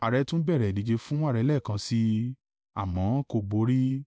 ttsOutput.wav